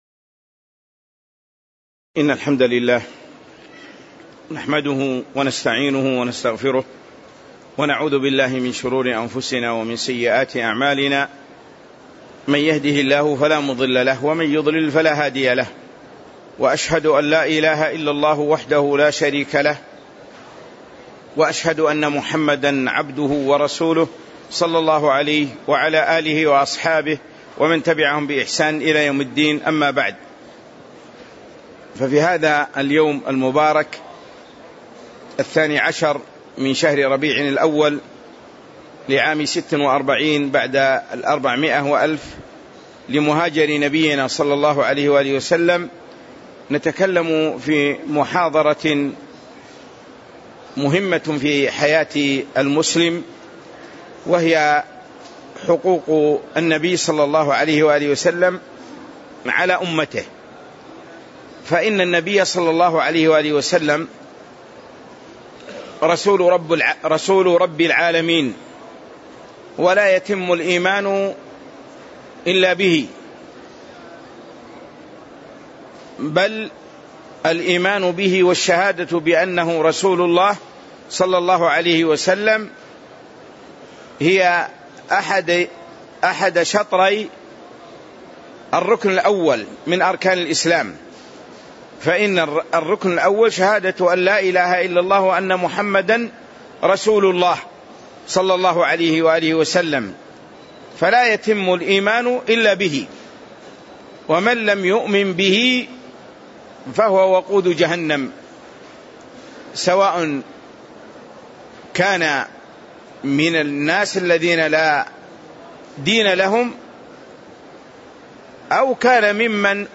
تاريخ النشر ١٢ ربيع الأول ١٤٤٦ هـ المكان: المسجد النبوي الشيخ